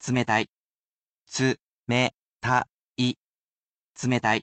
I am sure to read these aloud for you as not to leave you without a way to use them, sounding them out moji by moji.